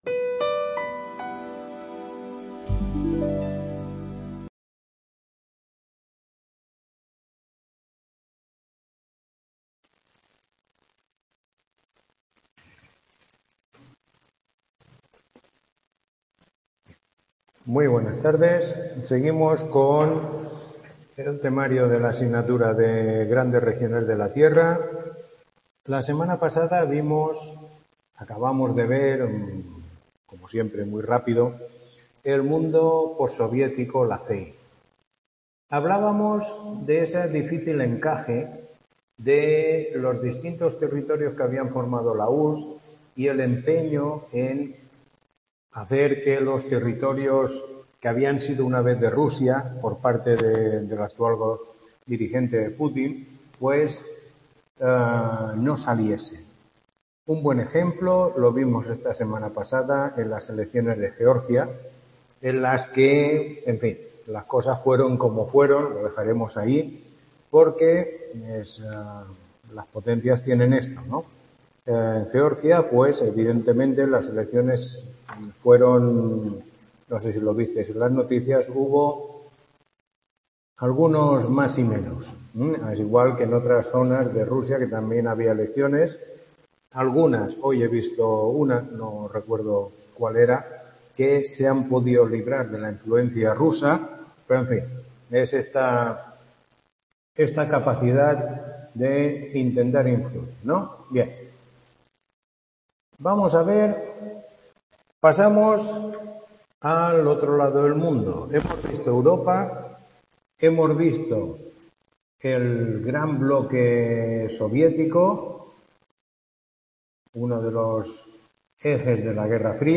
Tutoría 05